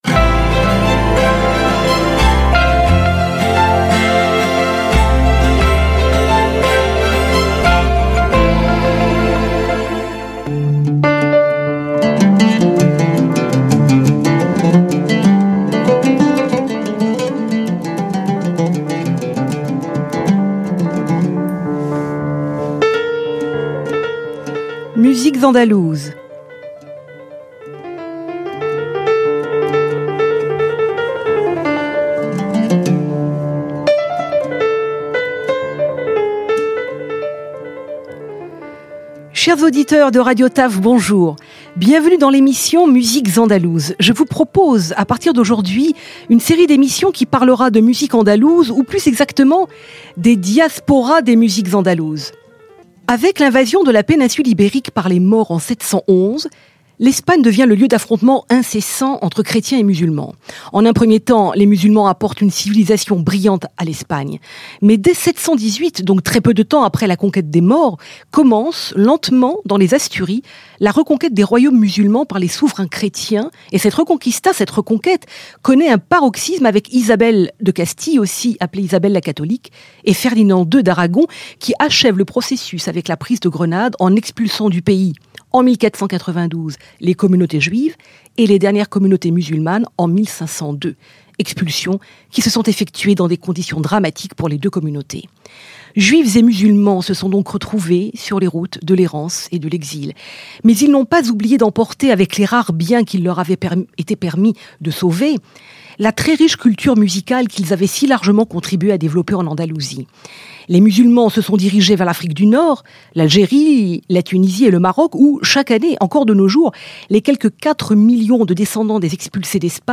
A radio show broadcasted on Radio Tel Aviv